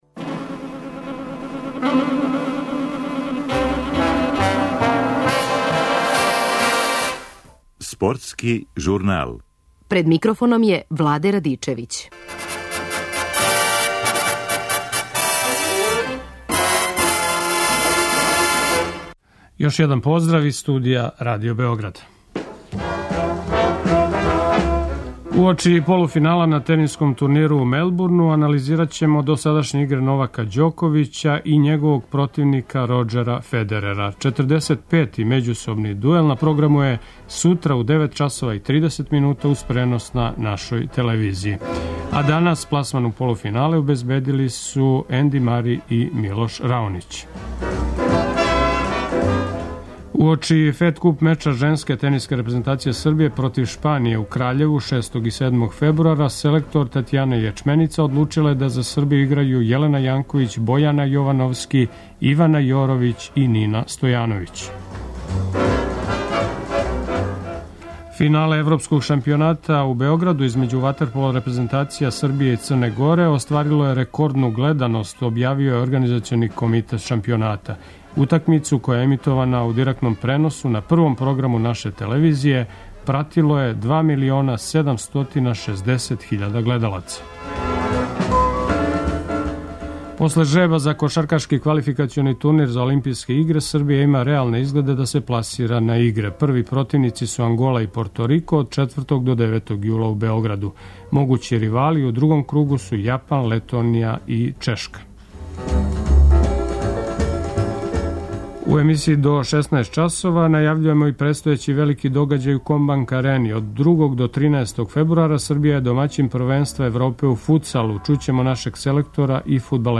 Од 2. до 13. фебруара Србија је домаћин првенства Европе у футсалу. Чућемо нашег селектора и фудбалере.